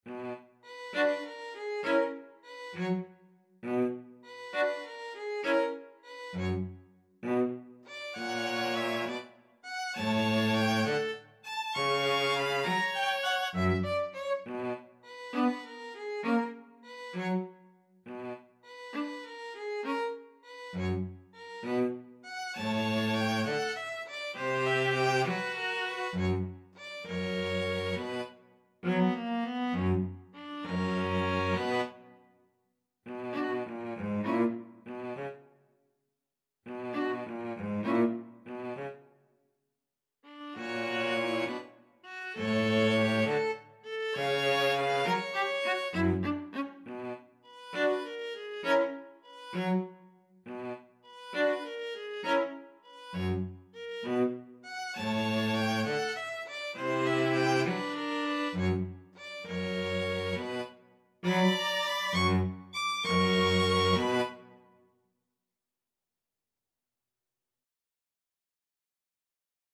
ViolinViolaCello
Moderato
6/8 (View more 6/8 Music)
Classical (View more Classical String trio Music)